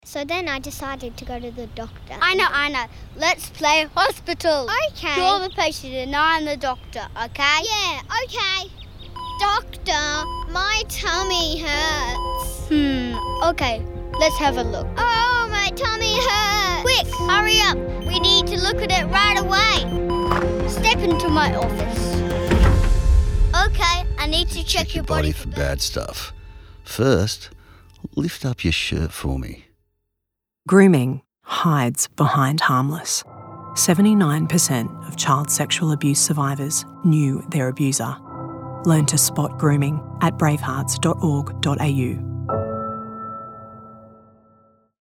Radio
The voice talent heard in the three chilling radio spots came from Brisbane-based voiceover agency SnackBox.